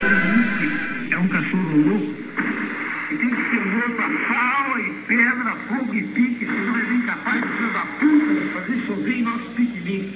clique para ouvir ouça Leminski neste poema (real audio 20,2kb)